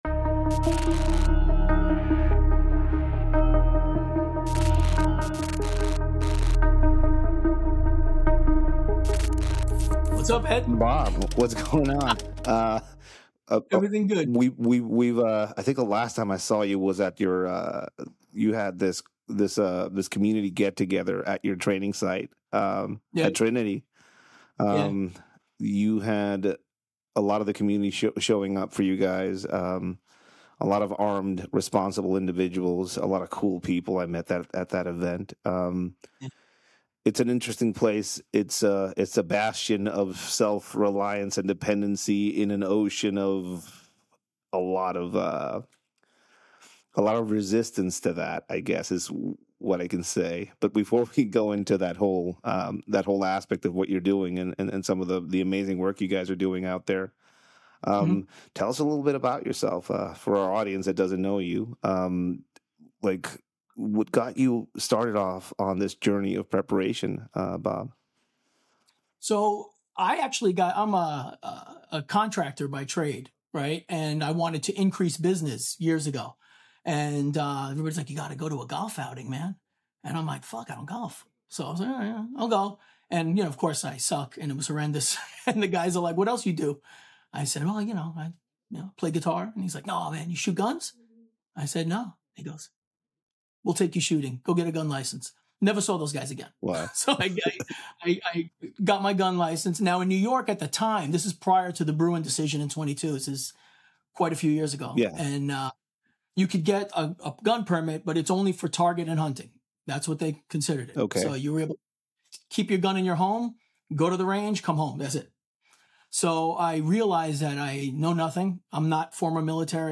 The conversation with him goes beyond technical training. It explores themes of self‑reliance, personal responsibility, and the importance of education.